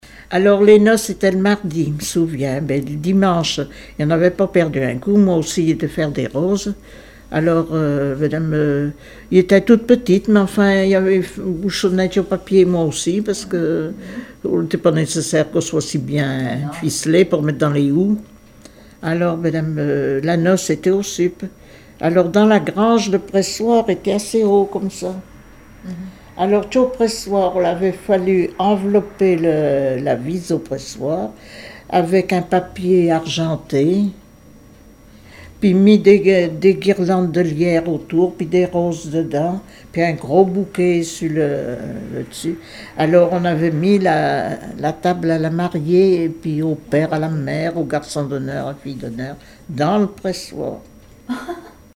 Enquête Arexcpo en Vendée-Association Joyeux Vendéens
Catégorie Témoignage